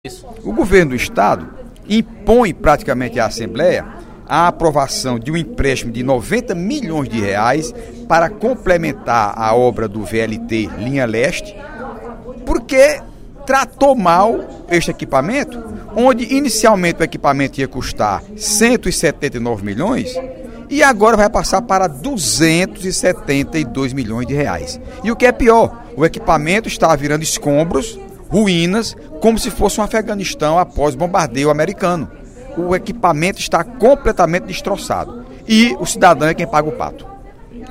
O deputado Heitor Férrer (PSB) criticou, nesta quarta-feira (04/05), durante o primeiro expediente da sessão plenária da Assembleia Legislativa, o projeto de lei, de iniciativa do Executivo, que prevê empréstimo da ordem de R$ 90 milhões. Os recursos seriam destinados à conclusão das obras do Veículo Leve sobre Trilhos (VLT).